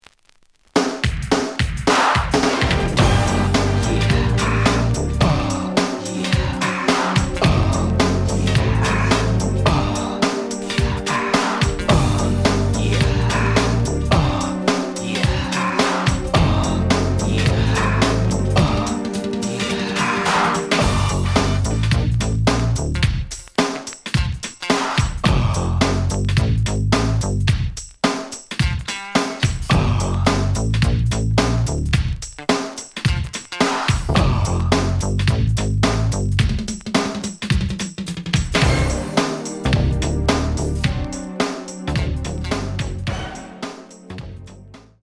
The audio sample is recorded from the actual item.
●Genre: Hip Hop / R&B
Slight edge warp.